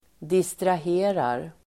Uttal: [distrah'e:rar]